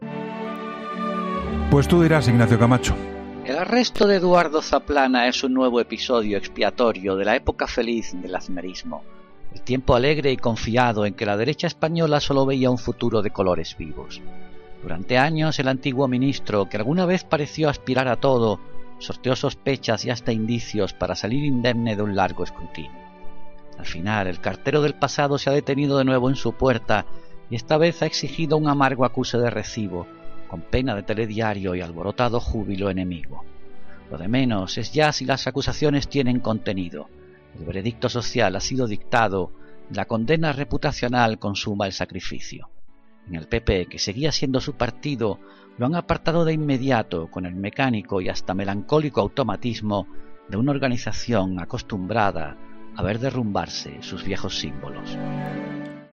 Ignacio Camacho habla en 'La Linterna' sobre la detención de Eduardo Zaplana